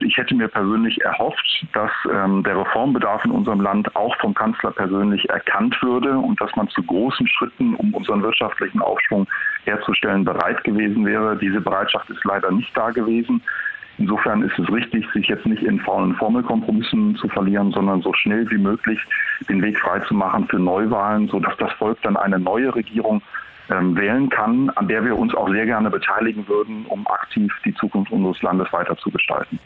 Der FDP-Bundestagsabgeordnete Jens Brandenburg (Wahlkreis Rhein-Neckar) sagte dem SWR in einer ersten Reaktion, er habe gehofft, dass "der Reformbedarf vom Kanzler erkannt wird" und dass man "zu großen Schritten" bereit gewesen wäre, um zu einem Aufschwung der deutschen Wirtschaft beizutragen.